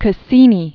(kə-sēnē)